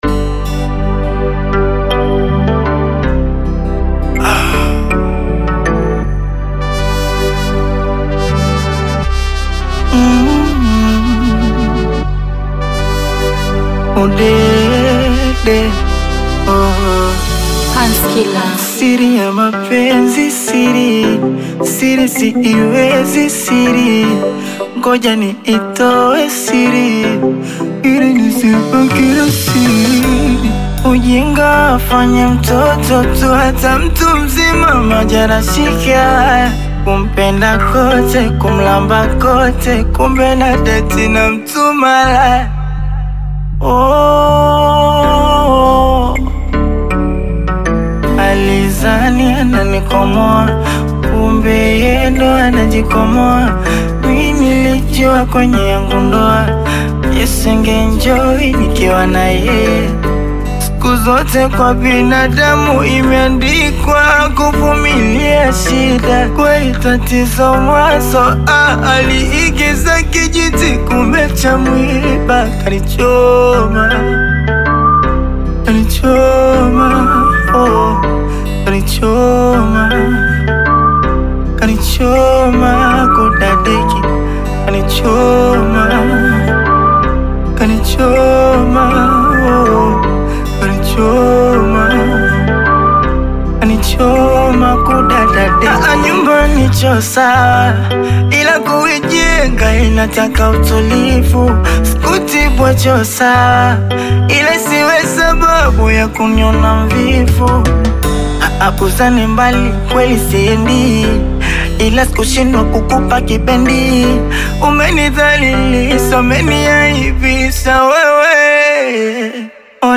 Afro-Pop single
Genre: Bongo Flava